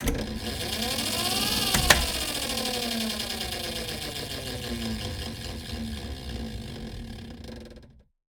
transportaccesstube.ogg